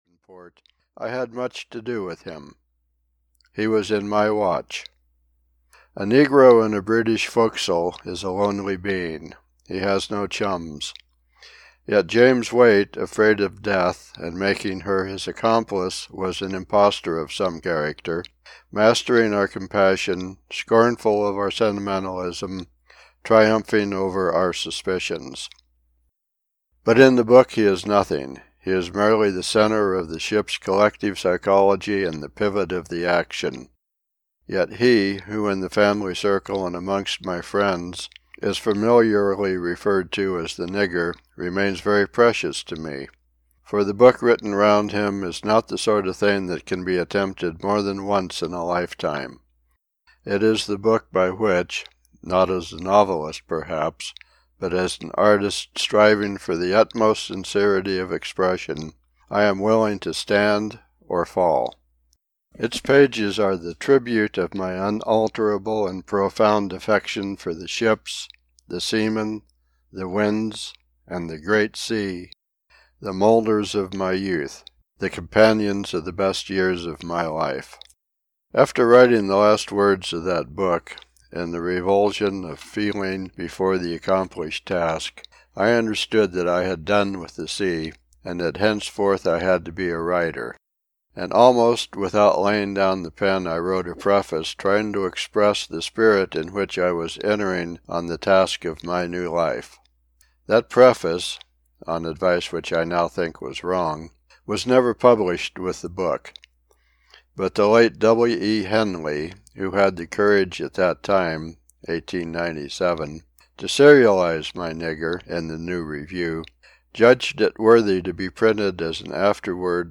The Children of the Sea (EN) audiokniha
Ukázka z knihy